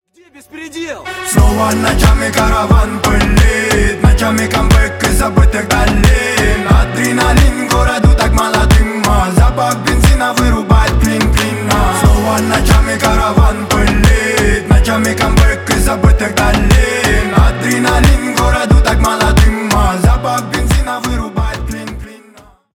Рэп и Хип Хоп